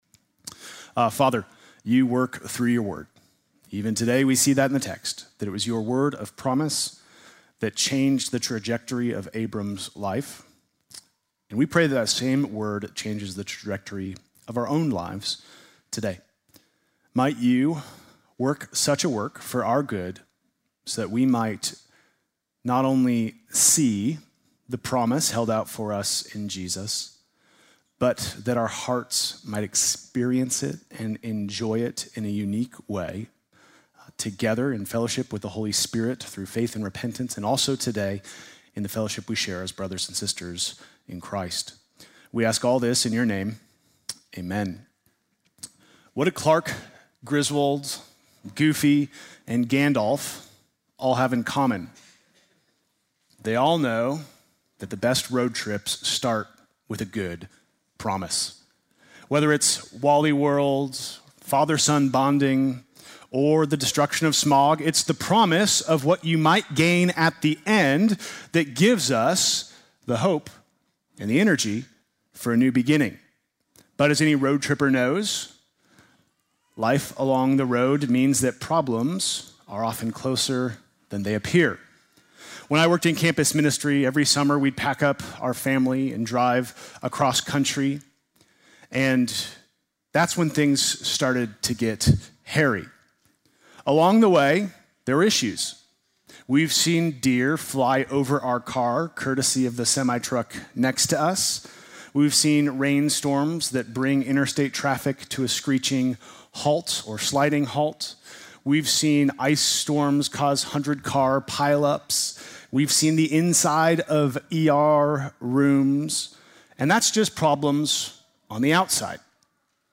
Sunday morning message January 25